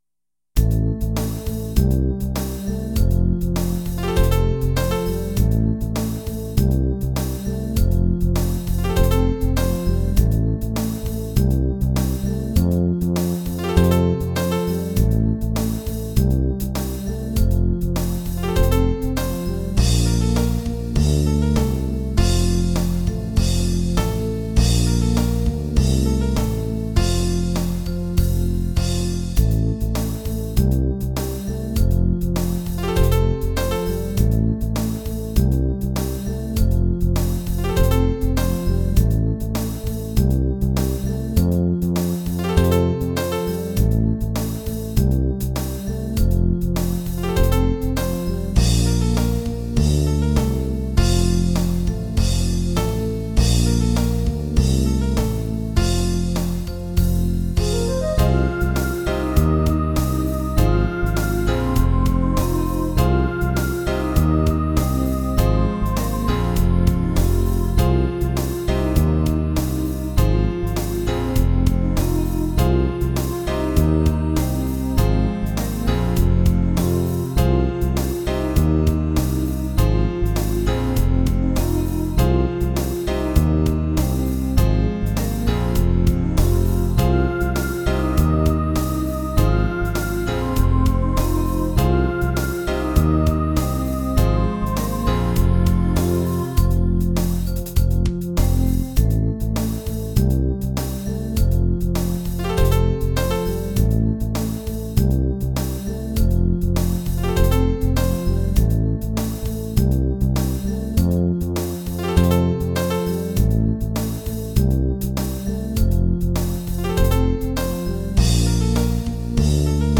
Syncapated bassline.